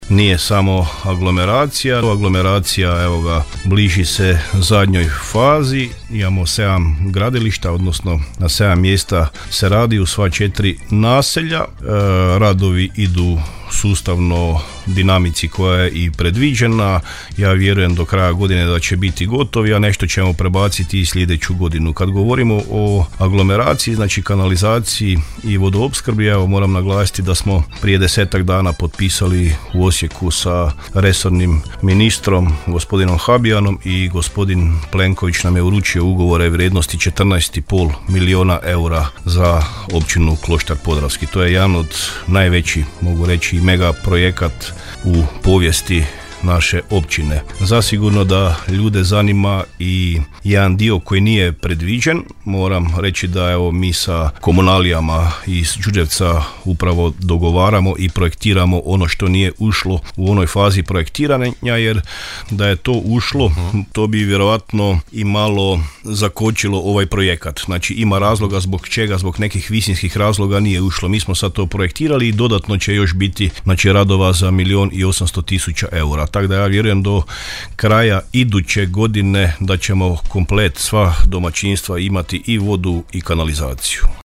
Općina Kloštar Podravski sa svim svojim naseljima jedno je veliko gradilište, rekao je u emisiji Susjedne općine u programu Podravskog radija, načelnik Općine Kloštar Podravski, Siniša Pavlović;